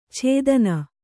♪ chēdana